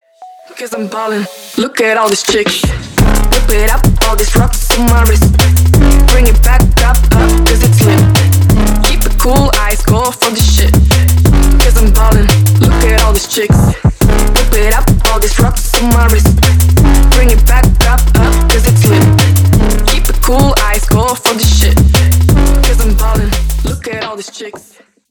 • Качество: 320, Stereo
женский голос
мощные басы
качающие
динамичные
драм энд бейс